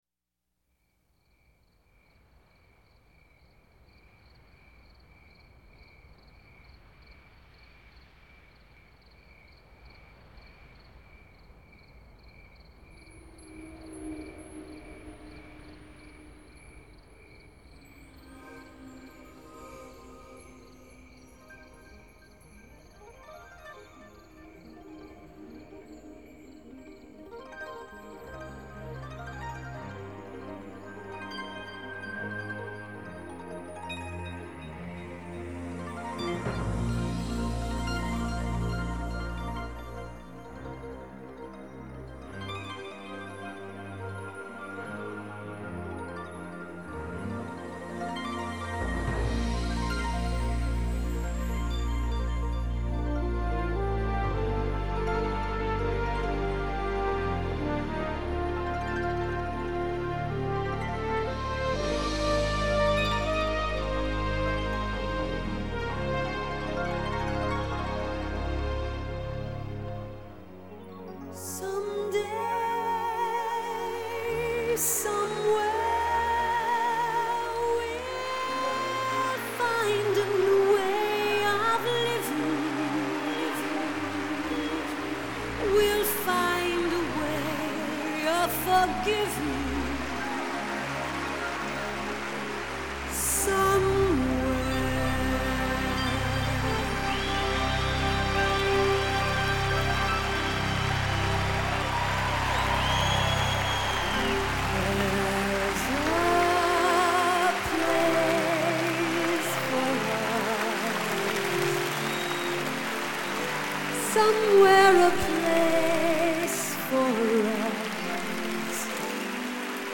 US • Genre: Pop